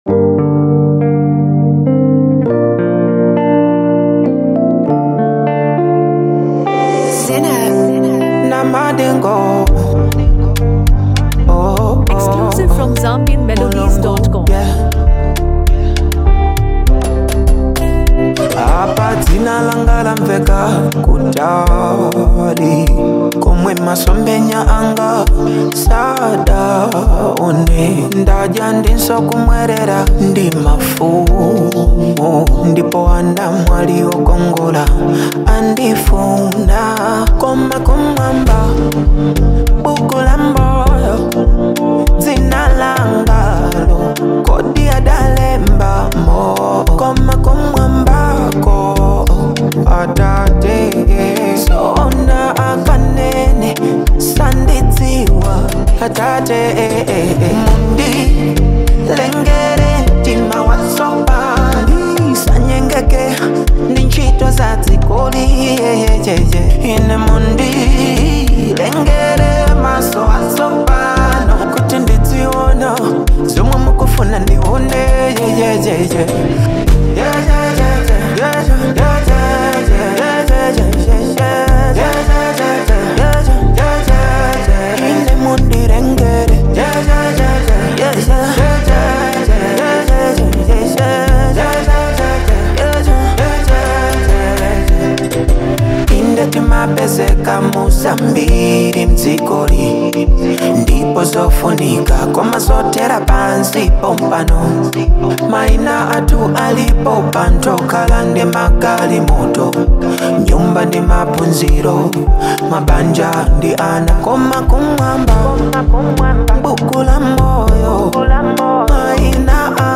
refreshing and uplifting inspirational song
known for his rich vocals and emotional depth
smooth melodies
praising its relatable message and uplifting tone.